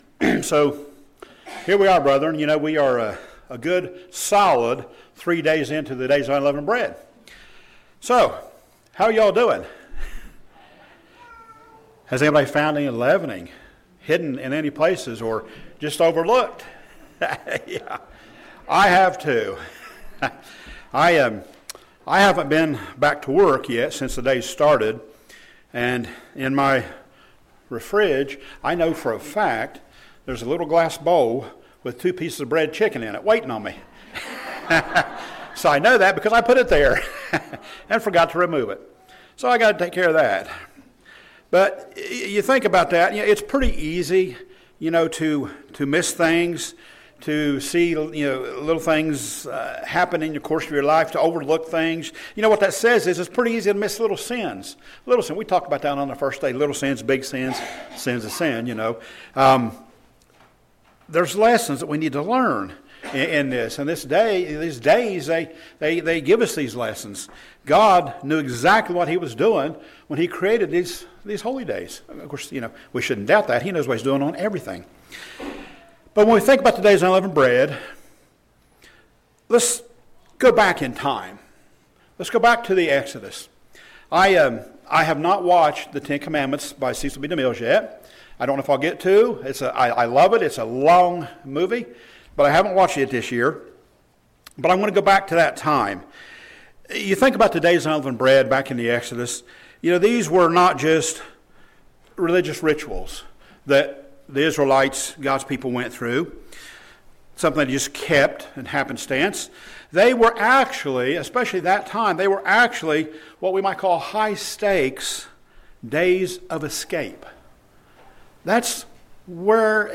Sermons
Given in Portsmouth, OH Paintsville, KY